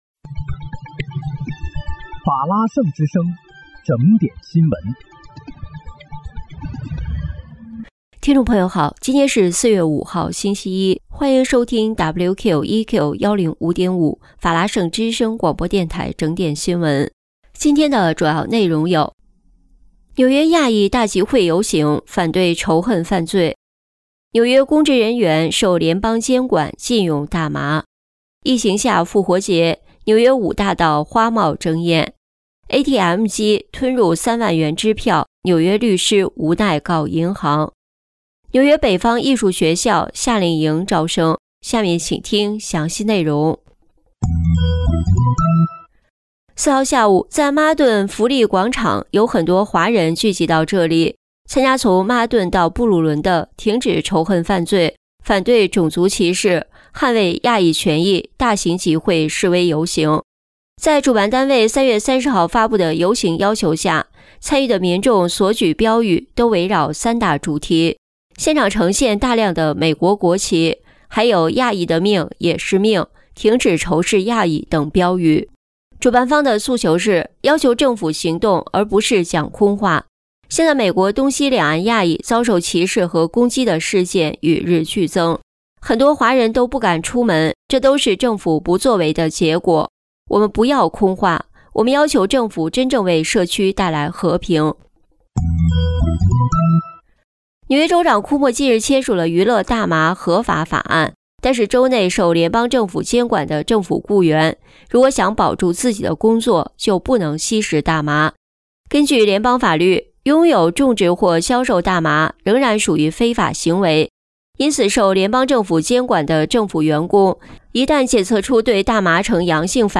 4月5日（星期一）纽约整点新闻